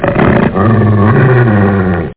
GROWL1.mp3